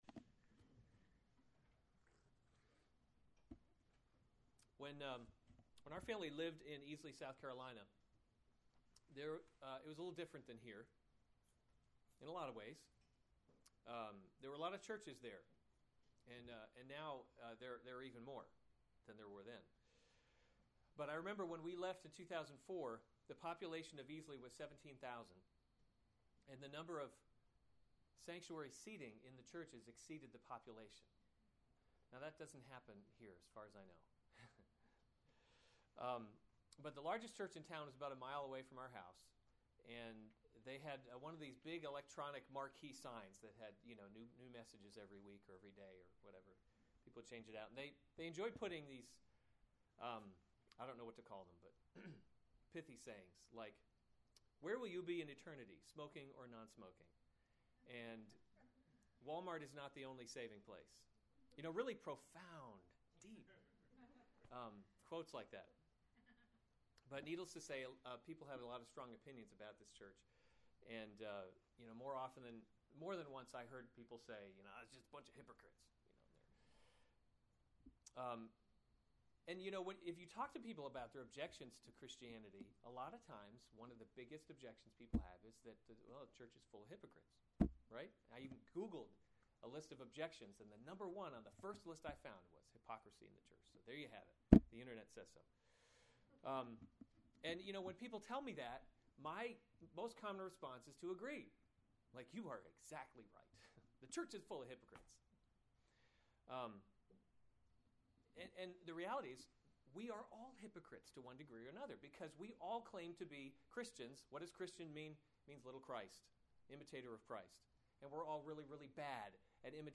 July 25, 2015 Psalms – Summer Series series Weekly Sunday Service Save/Download this sermon Psalm 32 Other sermons from Psalm Blessed Are the Forgiven A Maskil [1] of David. 32:1 Blessed is […]